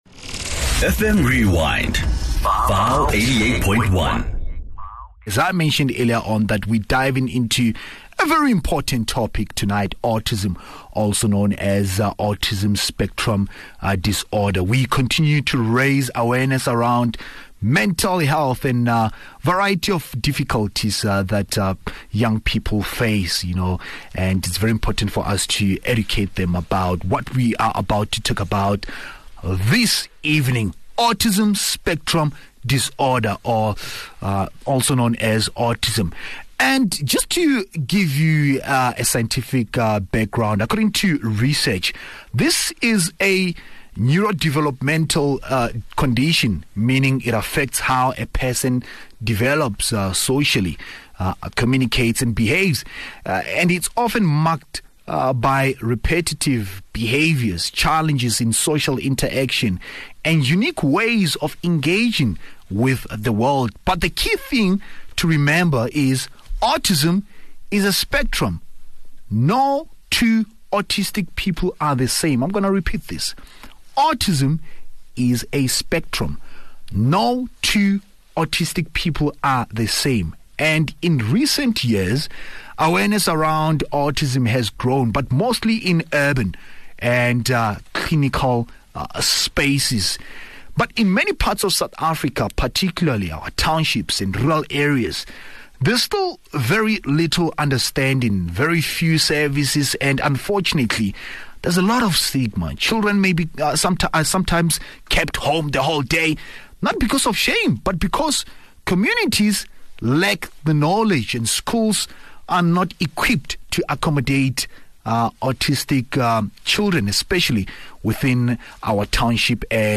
In conversation with